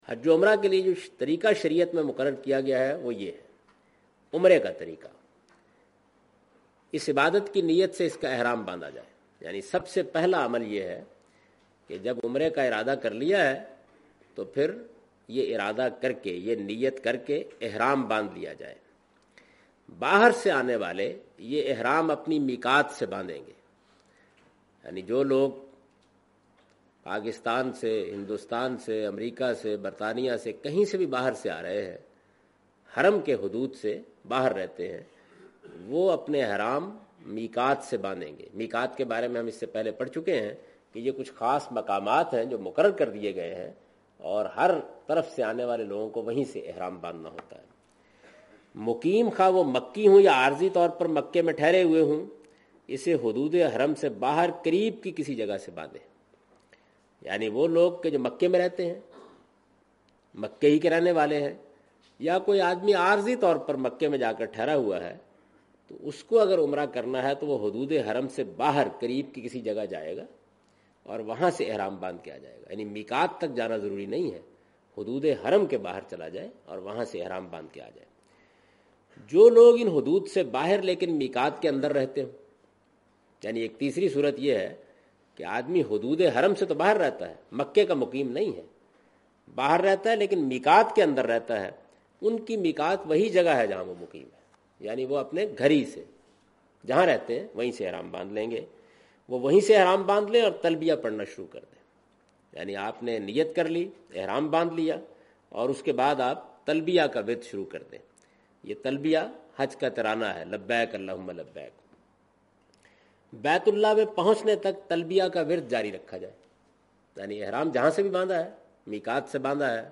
In this video of Hajj and Umrah, Javed Ahmed Ghamdi is talking about "Method of Performing Umrah".
حج و عمرہ کی اس ویڈیو میں جناب جاوید احمد صاحب غامدی "عمرہ ادا کرنے کا طریقہ" سے متعلق گفتگو کر رہے ہیں۔